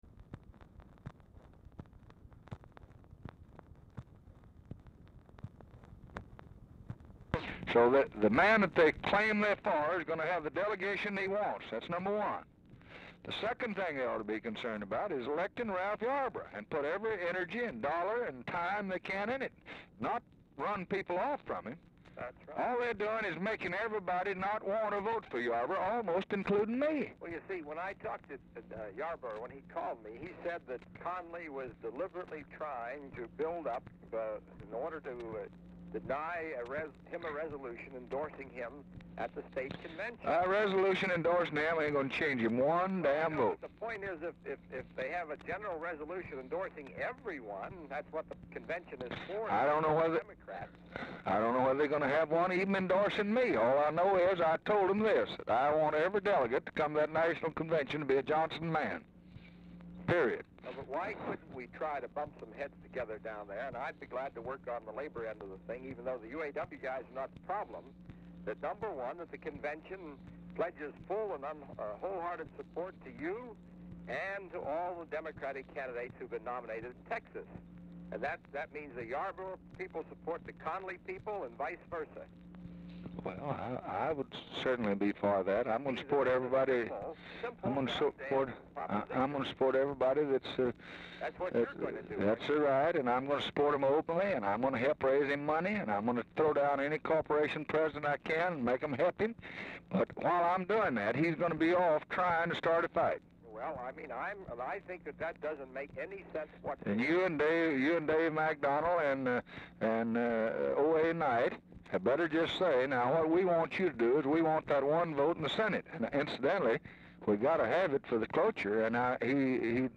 Location of Speaker 1: Oval Office or unknown location
Specific Item Type: Telephone conversation
Format: Dictation belt